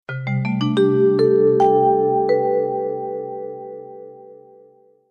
Kategorien SMS Töne